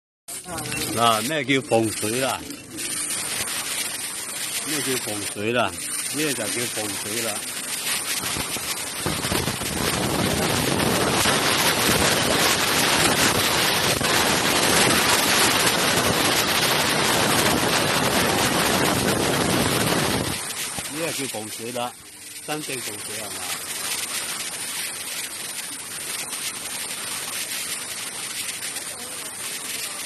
Testing 1500W Waterproof fog machine sound effects free download